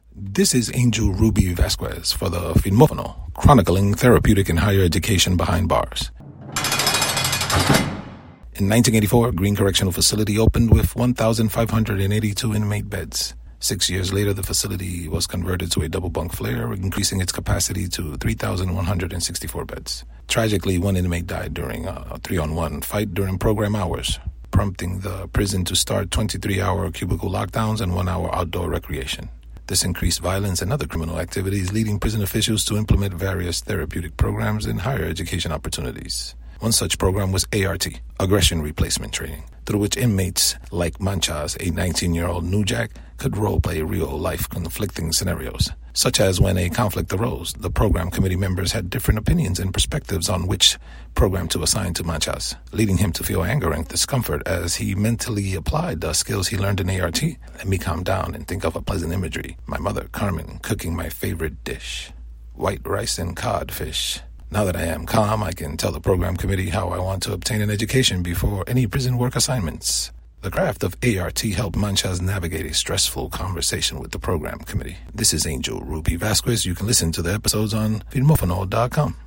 “Never Again” is a non-fictional podcast drama hosted through the biographer’s voice in an episodic narrative.